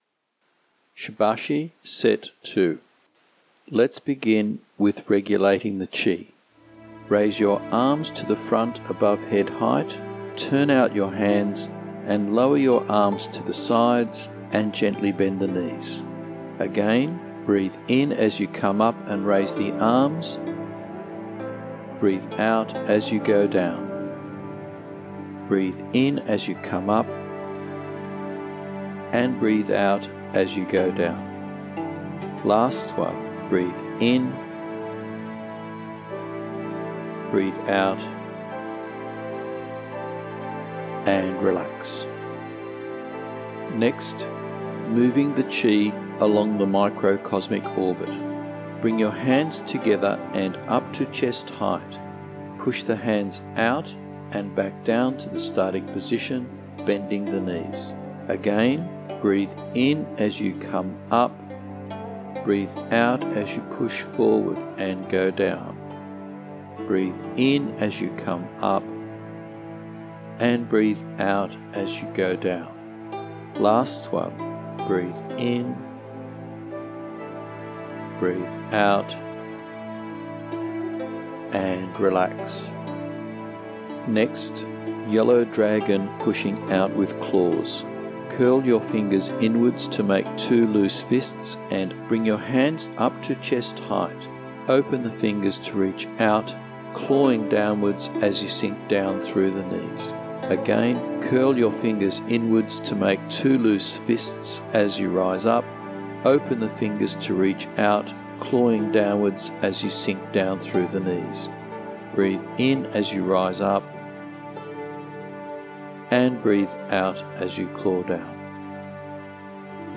Here are direct links to the MP3 audio files including the movements in English for teaching or practicing.